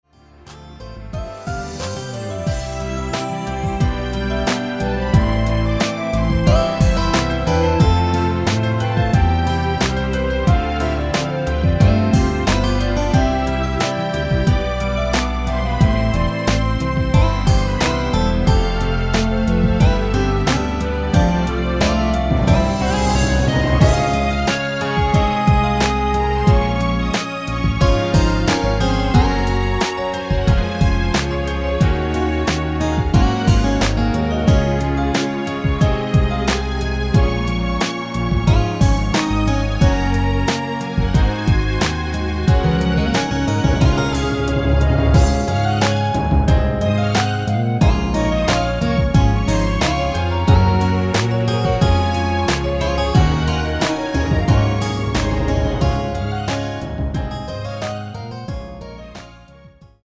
멜로디 포함된 MR 입니다.
앞부분30초, 뒷부분30초씩 편집해서 올려 드리고 있습니다.
중간에 음이 끈어지고 다시 나오는 이유는
(멜로디 MR)은 가이드 멜로디가 포함된 MR 입니다.